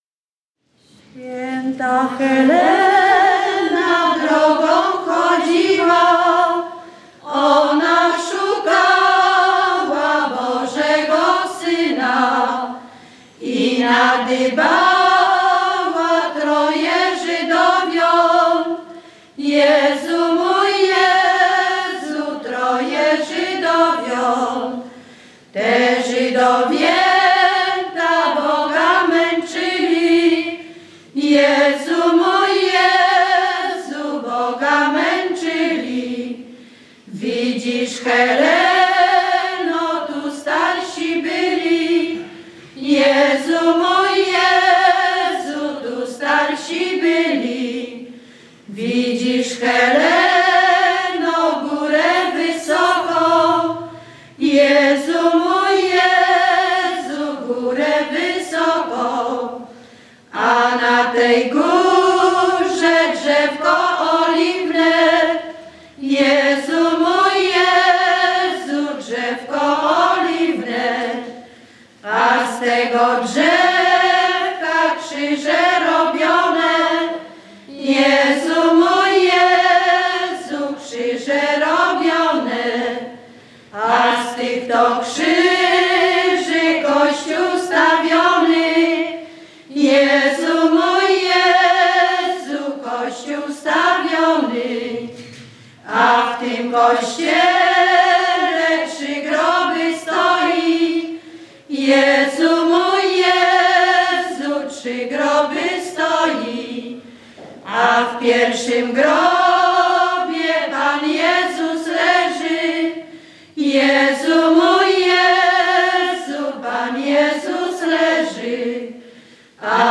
Zespół z Bukowej
województwo lubelskie, powiat biłgorajski, gmina Biłgoraj, wieś Bukowa
Pieśni o Świętych
nabożne katolickie o świętych dziadowskie